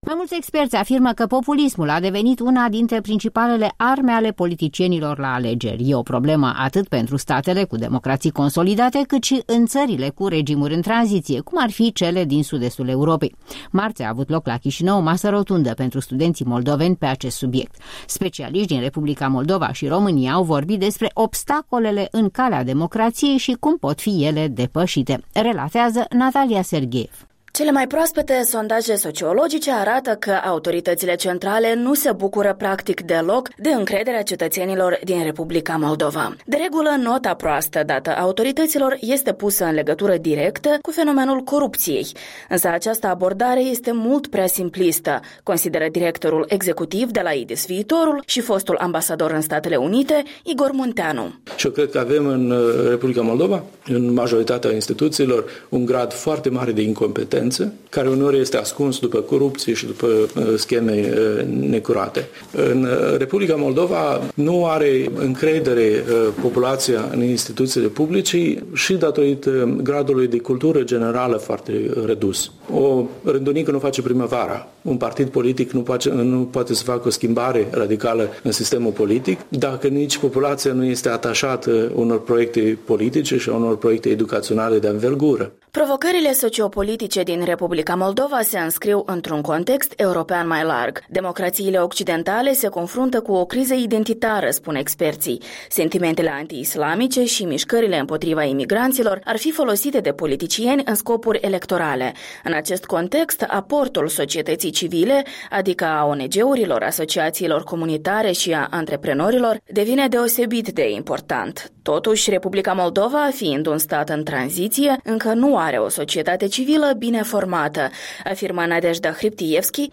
O dezbatere la Chișinău despre populism, democrație și stat de drept